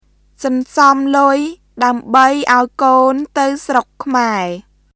[ソンソム・ルイ・ダウムバイ・アオイ・コーン・タウ・スロック・クマエ　sɔnsɔm lʊi daəmbəi ʔaoi koːn tə̀w srok kʰmae]